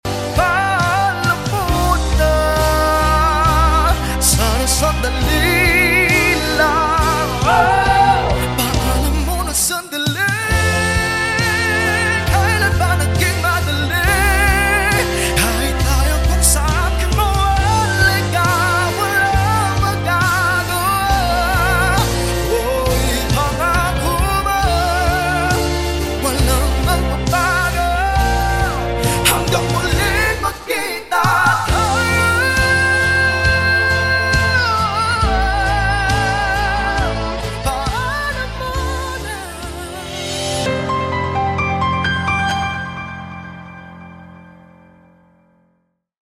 Full Cover